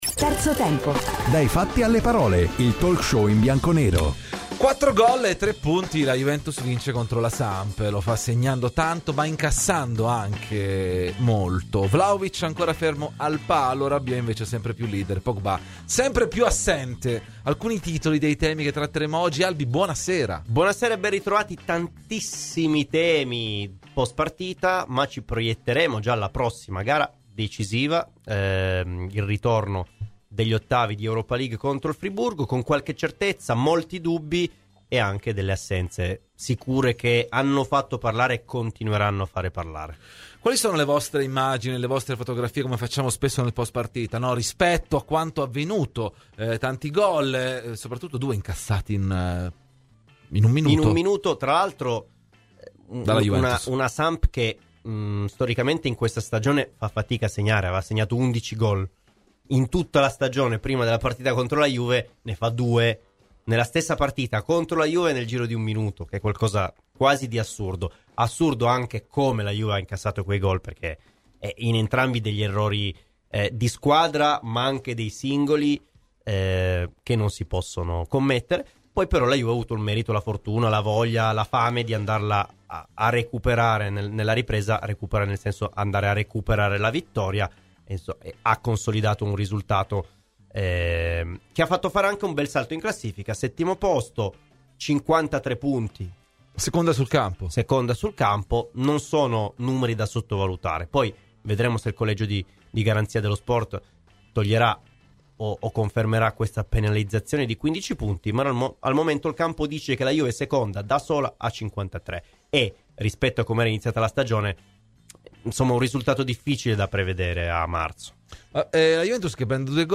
Intervistato da Radio Bianconera nel corso di 'Terzo Tempo'